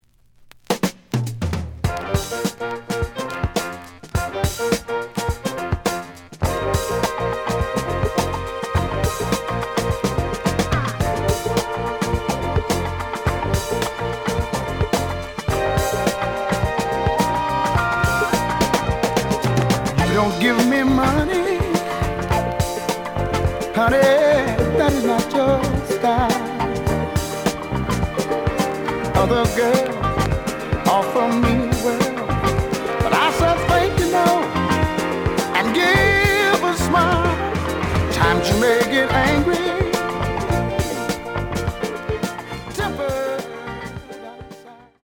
The audio sample is recorded from the actual item.
●Genre: Disco
B side plays good.)